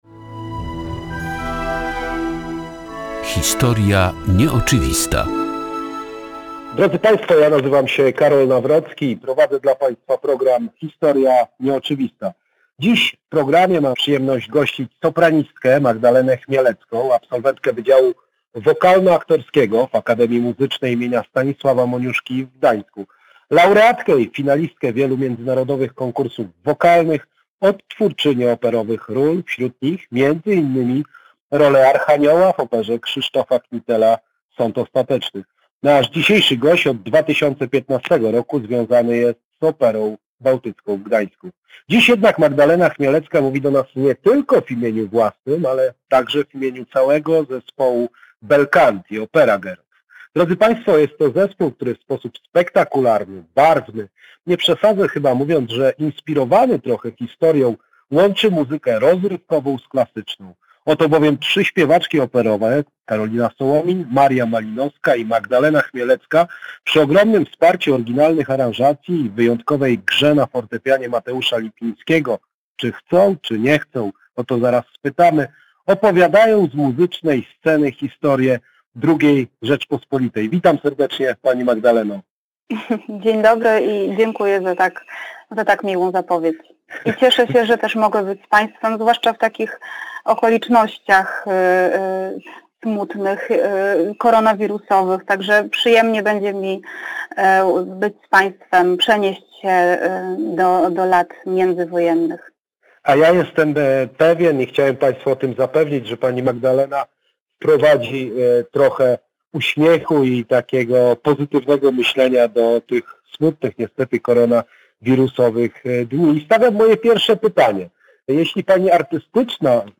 Rozmowa